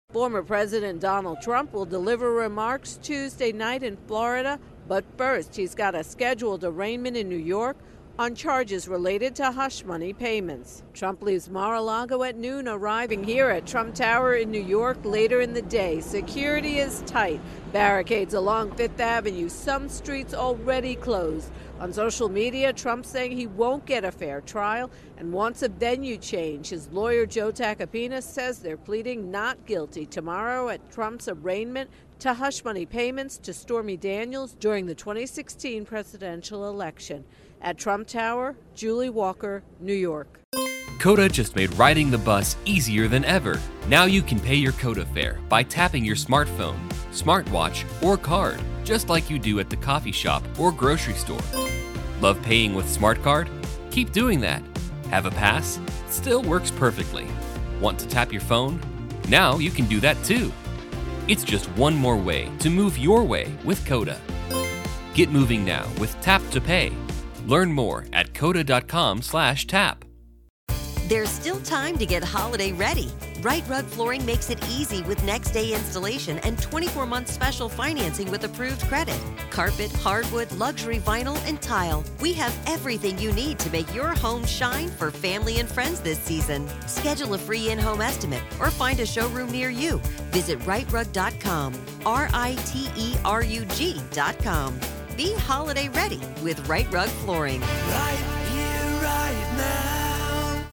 reports from New York on Trump Indictment